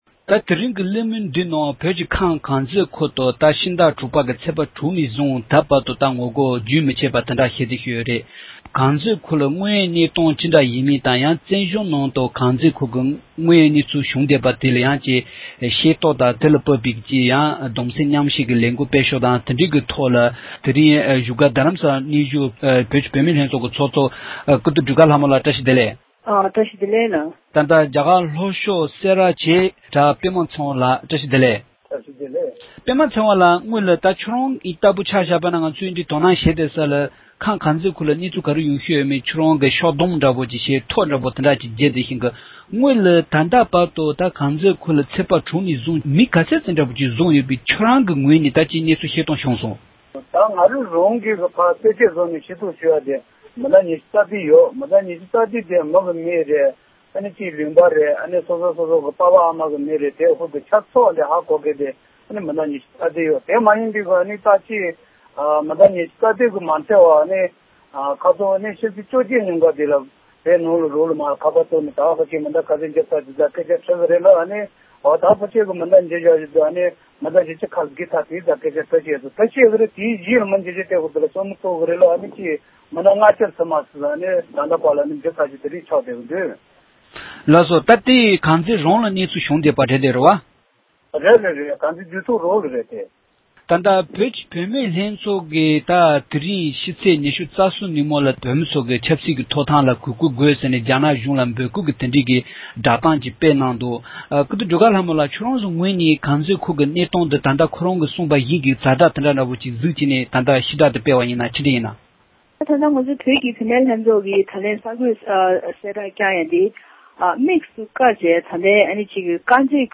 ཁམས་དཀར་མཛེས་ཁུལ་དུ་བོད་མི་ཚོས་ངོ་རྒོལ་བྱས་པ་ནི་སྒེར་གྱི་དོན་དུ་མ་ཡིན་པར་མི་རིཊ་དང་རྒྱལ་ཁབ་ཀྱི་ཆེད་དུ་ཡིན་པའི་ཐད་གླེང་མོལ།